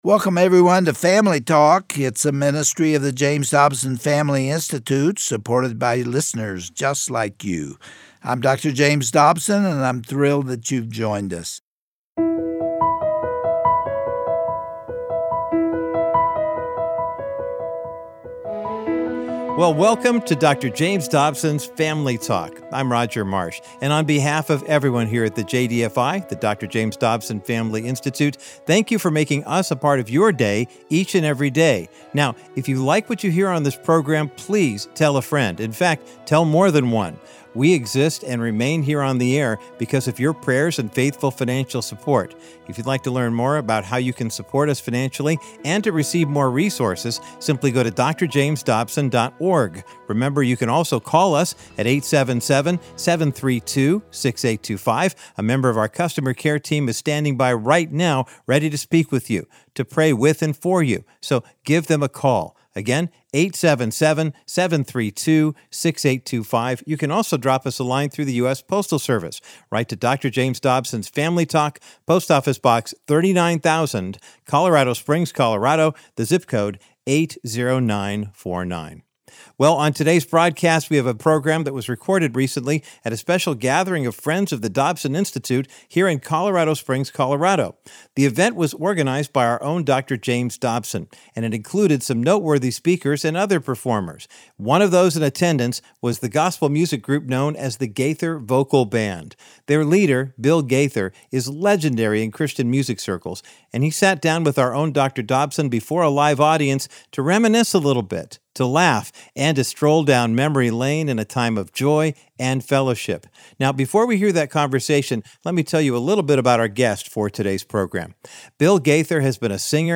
On today’s edition of Family Talk, Dr. James Dobson sits down with his dear friend, renowned gospel singer, Bill Gaither, to reminisce on their nearly half-century long friendship. You will delight in hearing these two godly men celebrate their fellowship through Scripture, song, and laughter.